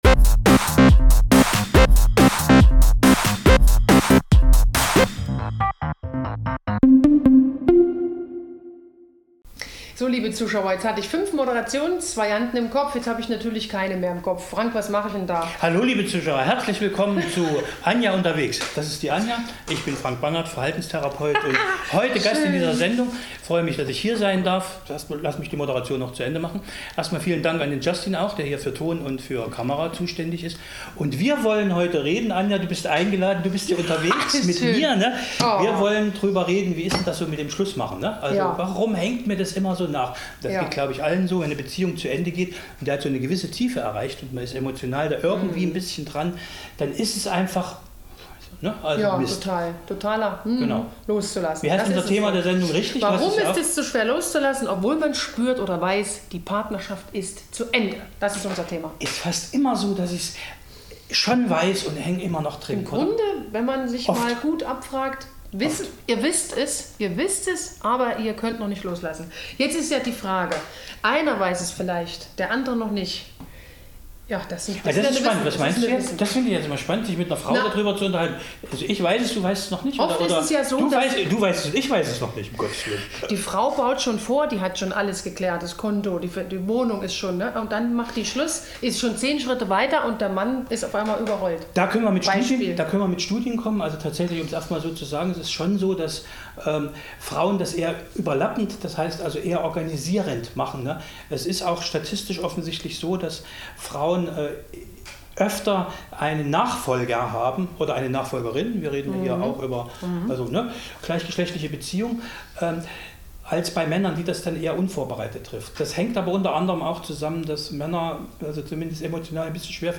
An dieser Stelle dokumentieren wir die Rede des AfD-Fraktionsvorsitzenden Bj�rn H�cke, mit der er den Antrag seiner Fraktion begr�ndet.